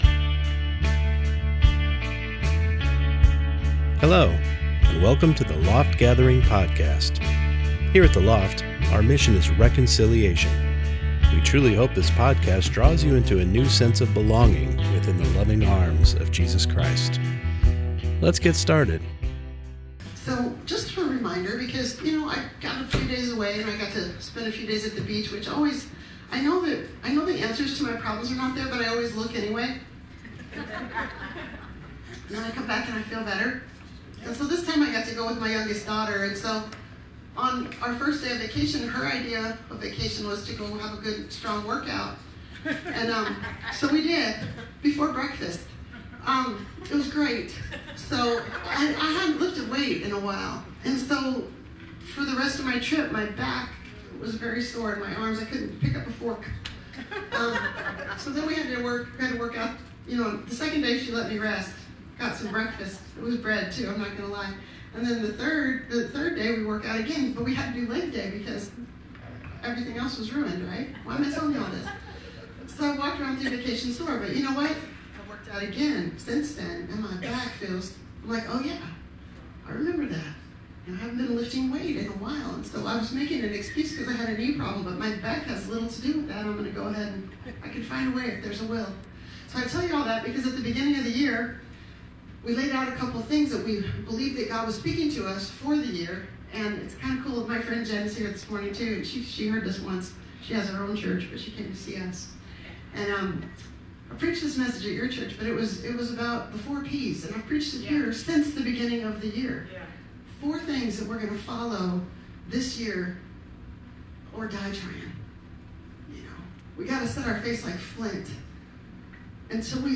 Sunday Morning Service Service: Sunday Morning Service The Gospel doesn’t leave room for messy grace or blatant discarding of instruction.